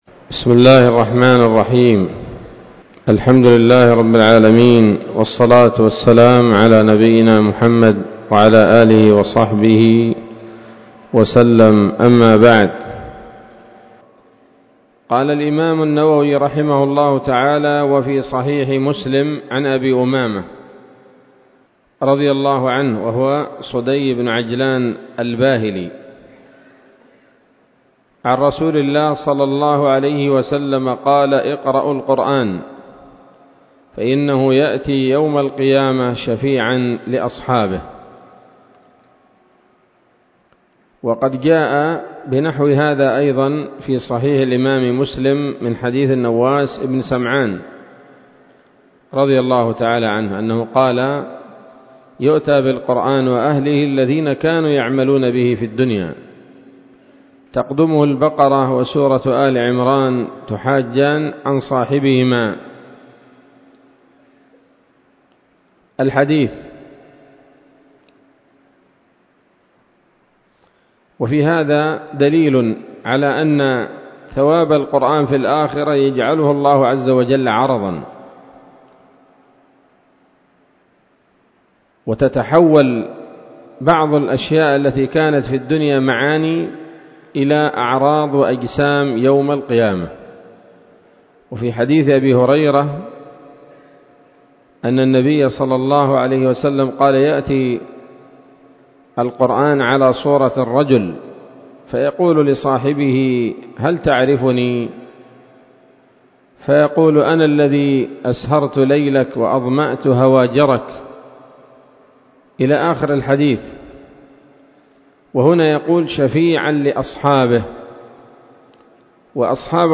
الدرس الثاني من مختصر التبيان في آداب حملة القرآن للنووي